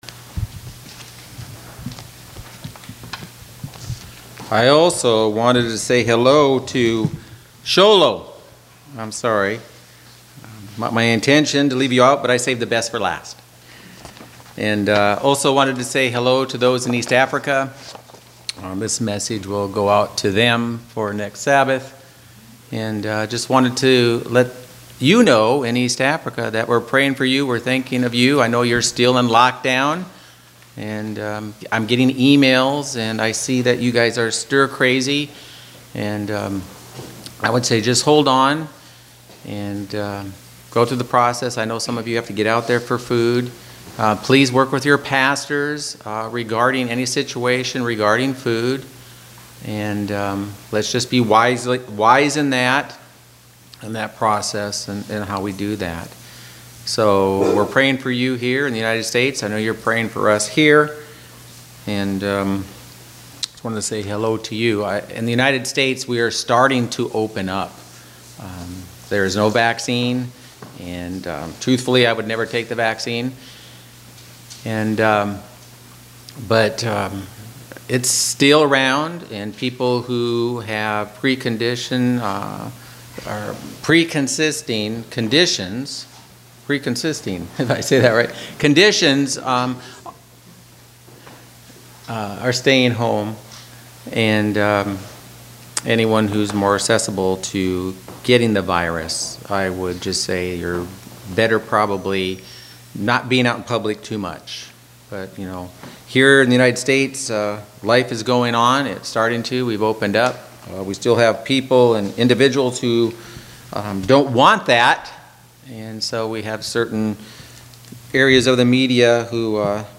Sermons
Given in Northern Arizona Phoenix East, AZ